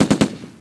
Browning Automatic Rifle (BAR) M1918A1 & M1918A2
Selective Fire (Semi-automatic & Fully-automatic)
bar.wav